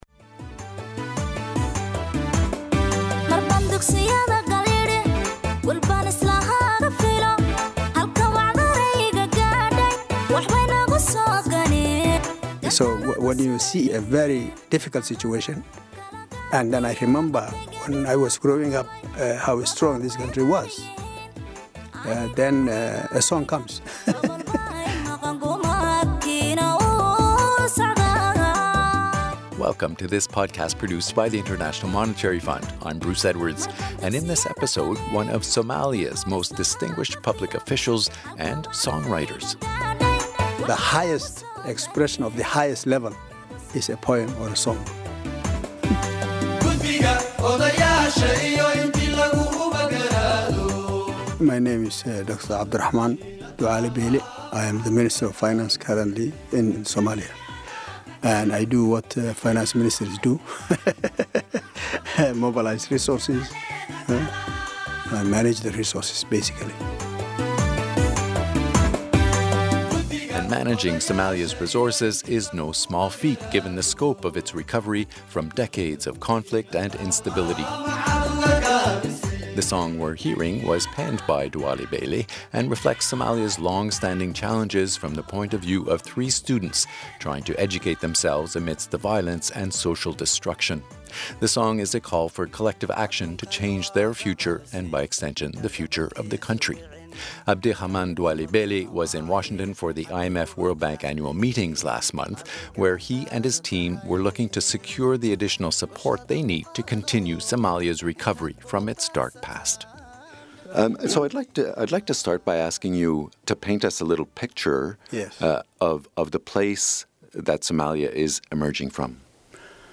Duale Beileh is also a distinguished artist and songwriter, and we hear his latest song about students struggling to get an education amid violence and social destruction.